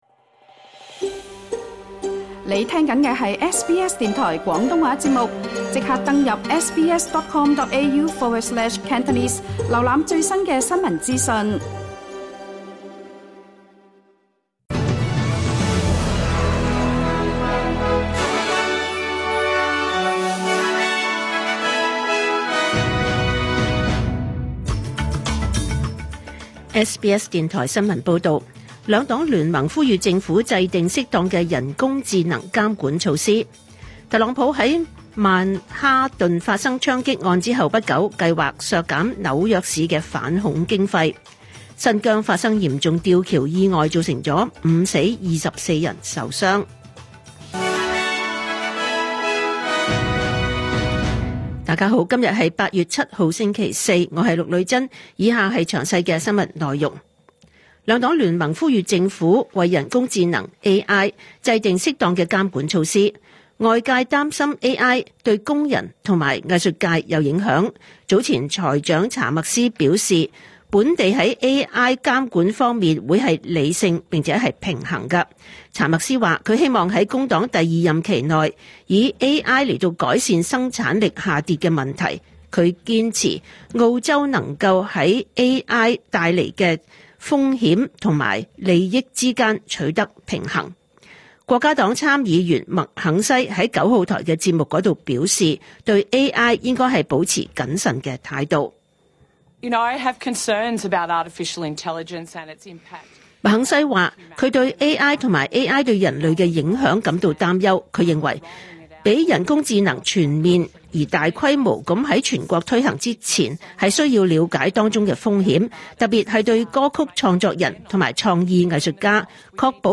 2025 年 8 月 7 日 SBS 廣東話節目詳盡早晨新聞報道。